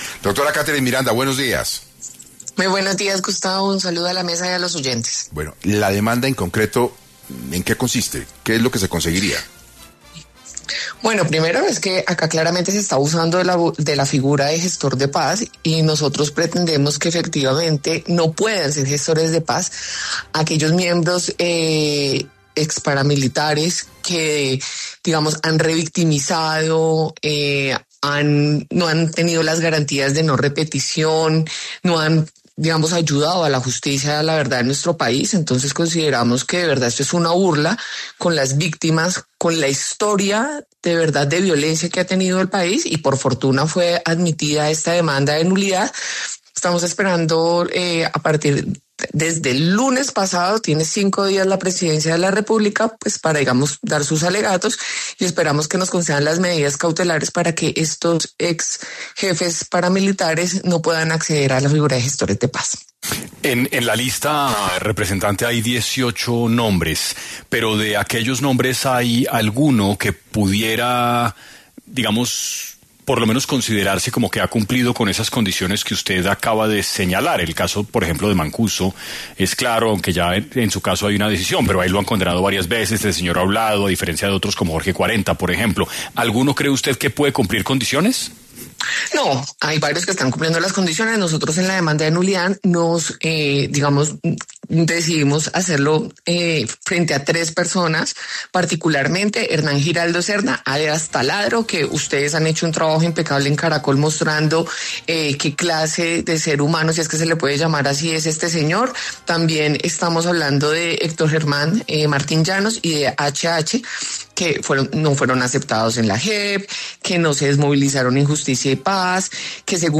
En 6AM de Caracol Radio estuvo Katherine Miranda, representante, para hablar sobre cómo planean frenar la designación de los 18 exjefes paramilitares como gestores de paz.